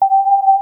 energyDetected.wav